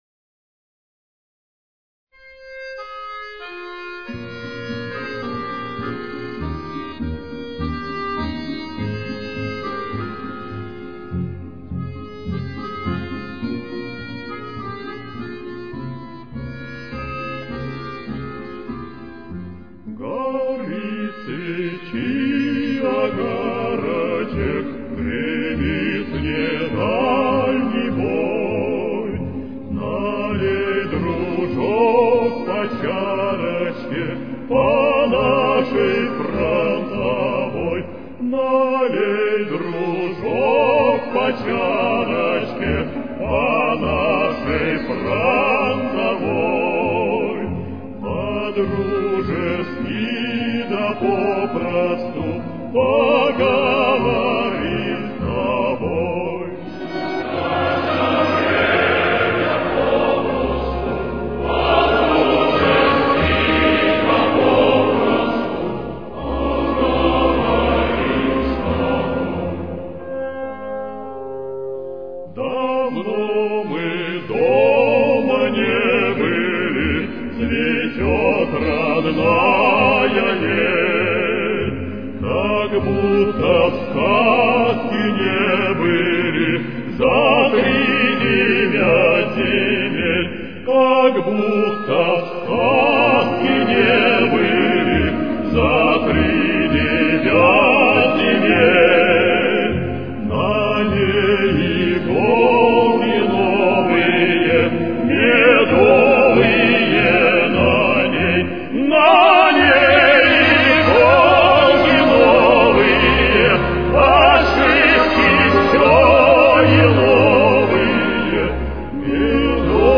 Фа минор. Темп: 51.